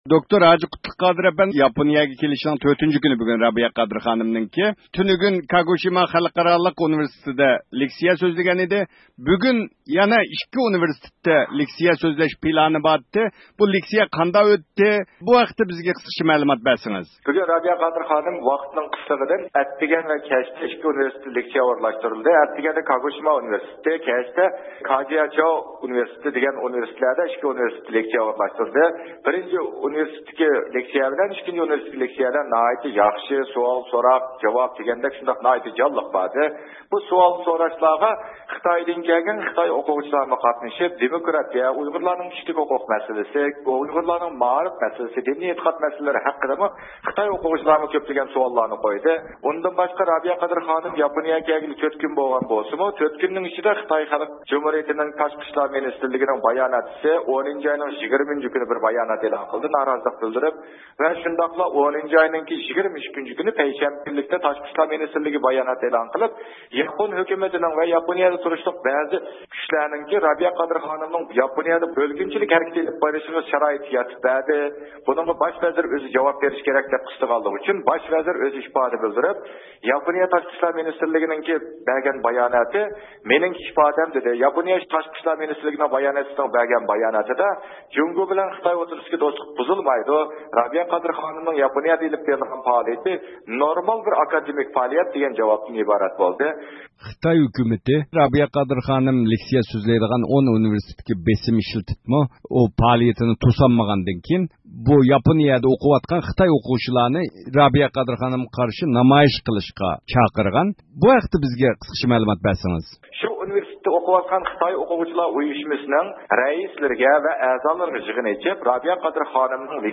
بىز، رابىيە قادىر خانىمنىڭ 23 – ئۆكتەبىردە ئىككى ئۇنىۋېرسىتېتتا بەرگەن دوكلاتى ھەققىدە تەپسىلىي مەلۇمات ئېلىش ئۈچۈن نەق مەيدانغا تېلېفون قىلىپ رابىيە قادىر خانىم ۋە باشقىلار بىلەن تېلېفون زىيارىتى ئېلىپ باردۇق.